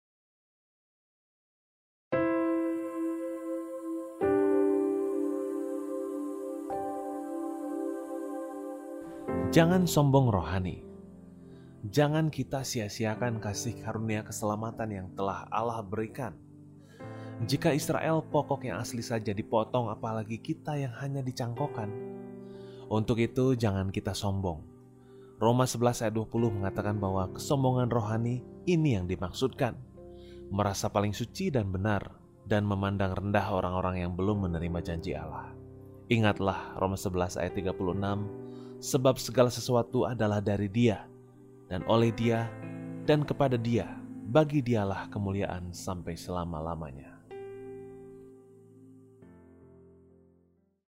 Renungan Audio